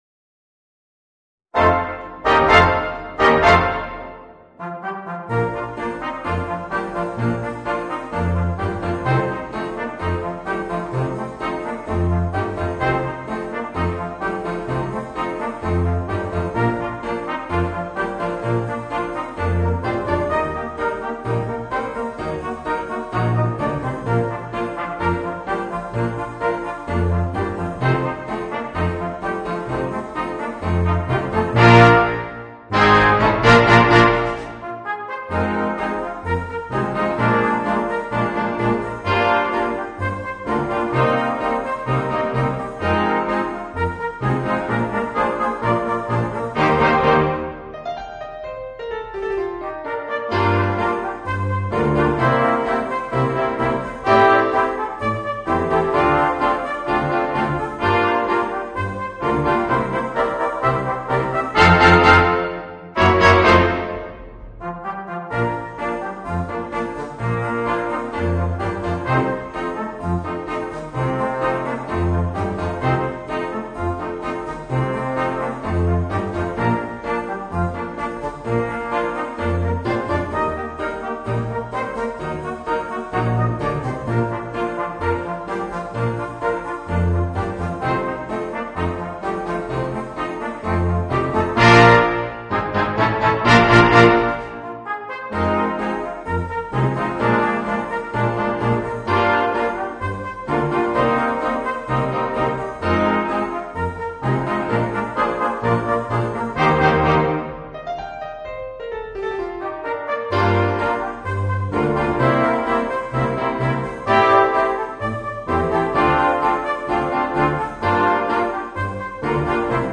Voicing: 5 - Part Ensemble